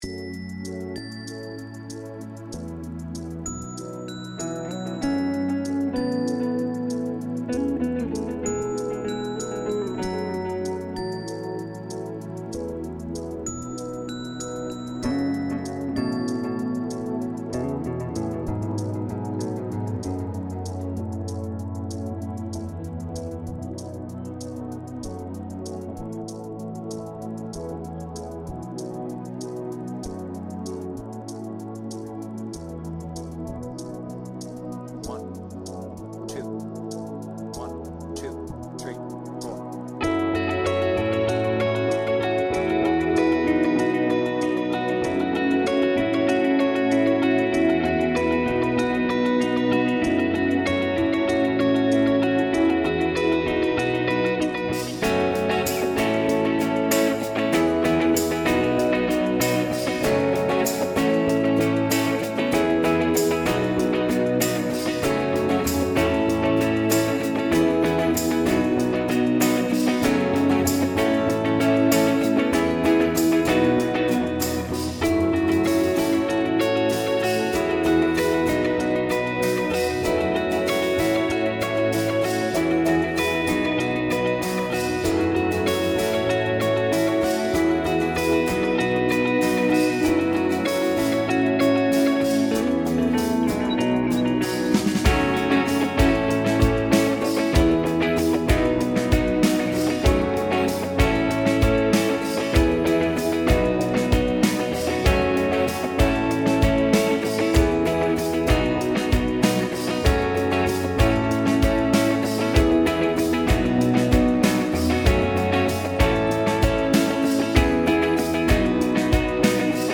BPM : 146
With Vocals